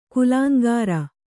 ♪ kulāŋgāra